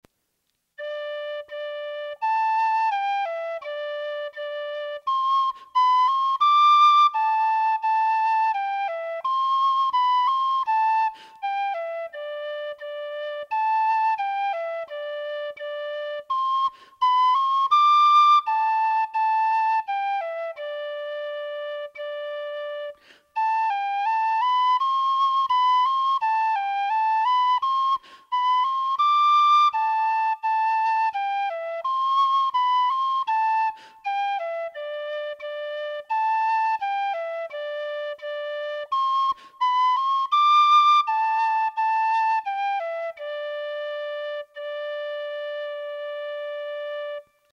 Traditional Irish Music - learning resources
Song Of The Chanter, The (March) /